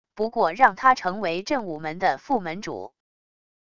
不过让他成为震武门的副门主wav音频生成系统WAV Audio Player